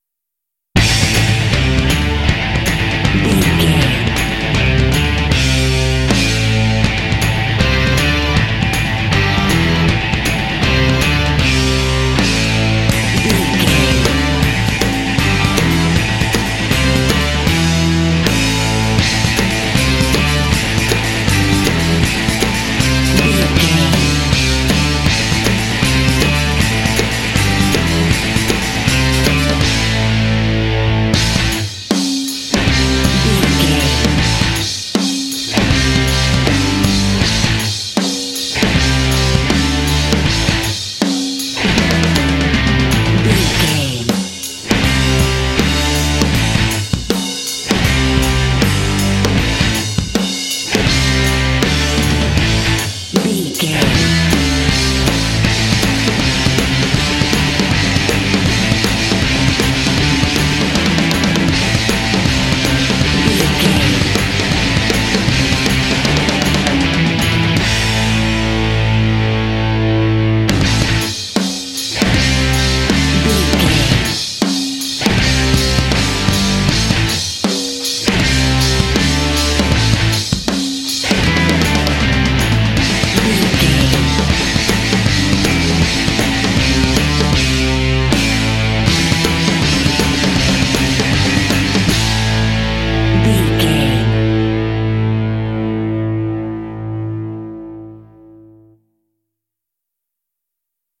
Epic / Action
Aeolian/Minor
energetic
lively
electric guitar
bass guitar
drums
hard rock
heavy metal